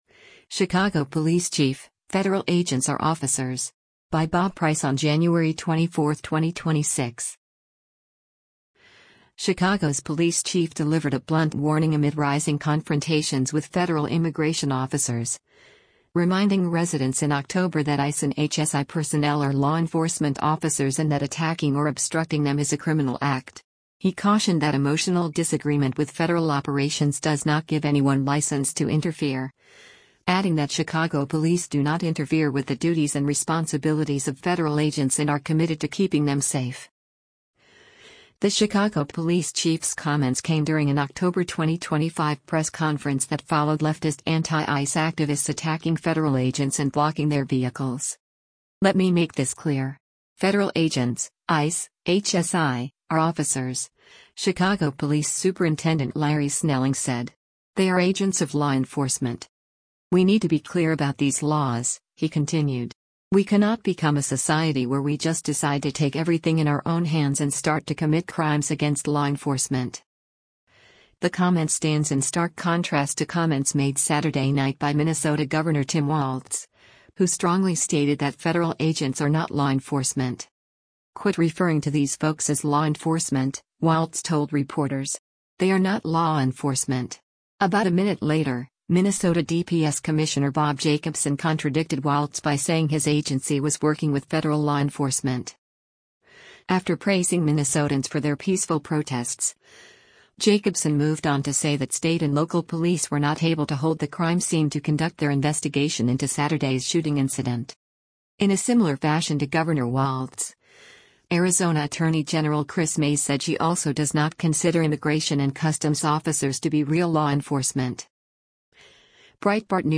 The Chicago police chief’s comments came during an October 2025 press conference that followed leftist anti-ICE activists attacking federal agents and blocking their vehicles.